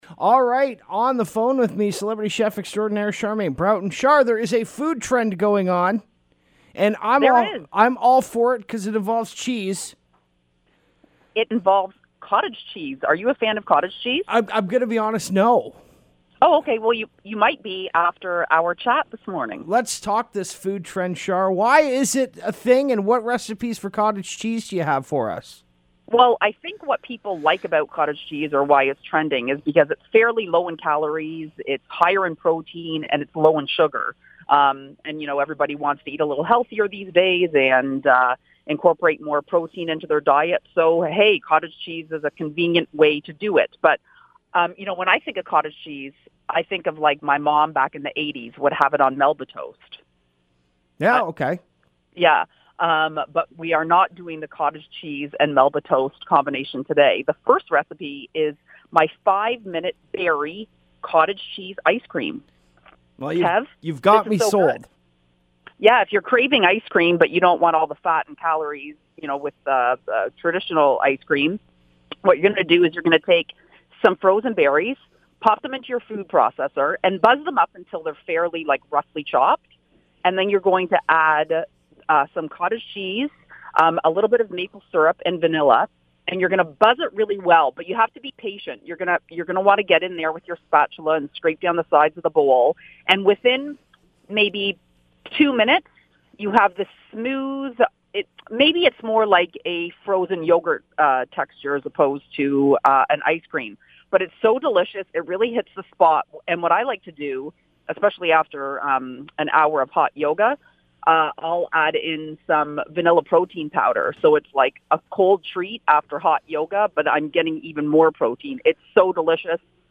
here is our interview!